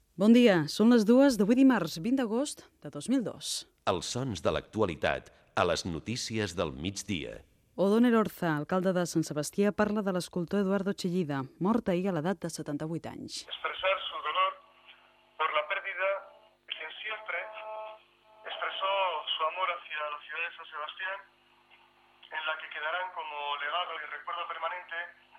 Data, indicatiu del programa. Mort de l'escultor Eduardo Chillida. Declaracions de l'alcalde de Sant Sebastià, Odón Elorza.
Informatiu